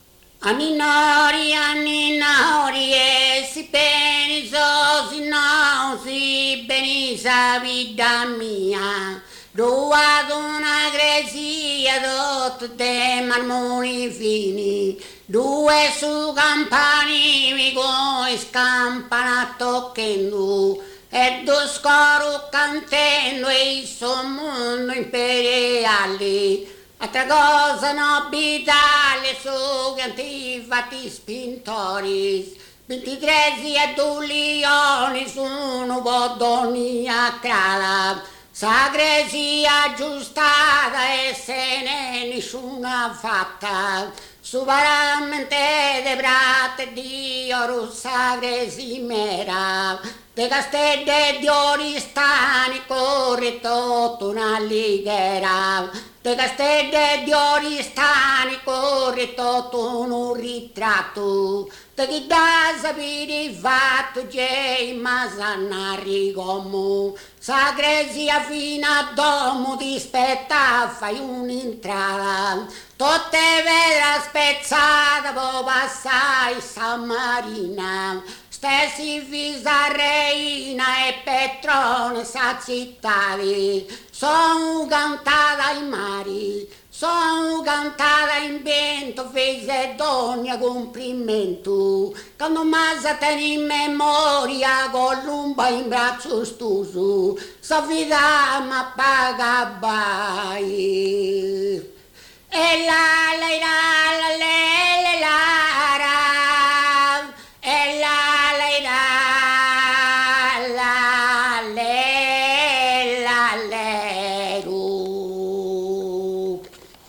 ballu